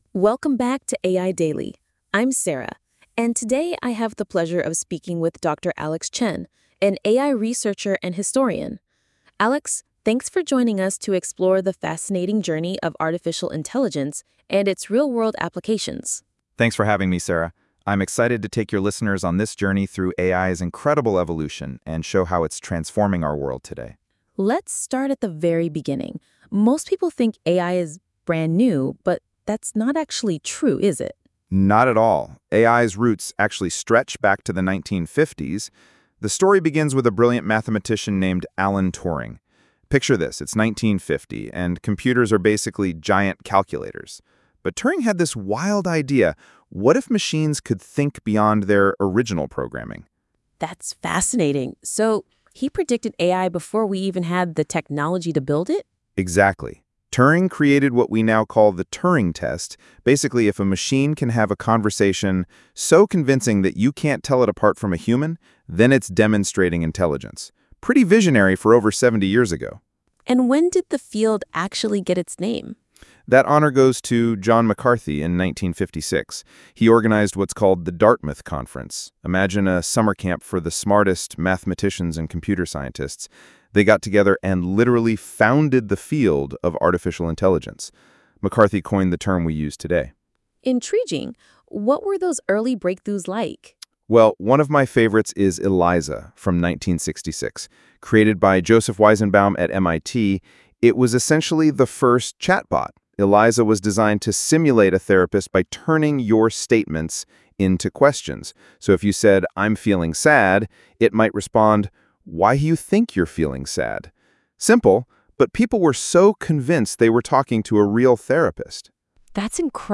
Welcome and guest introduction